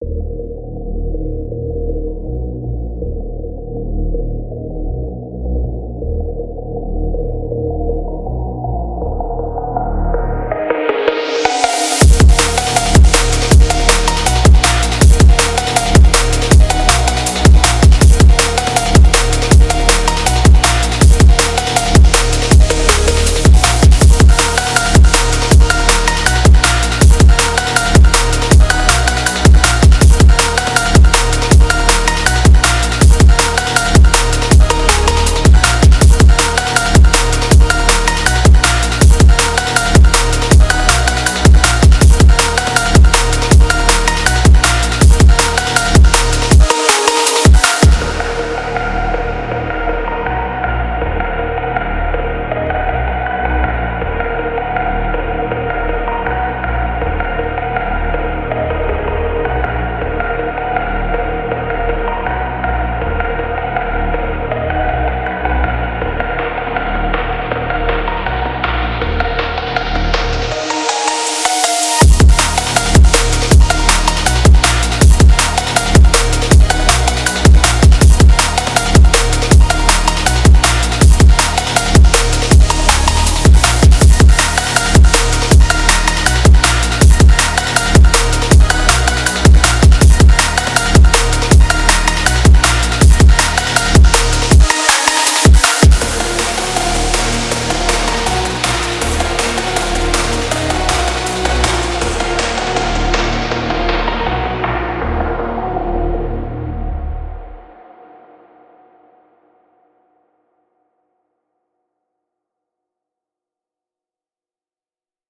Фонк музыка
Phonk музыка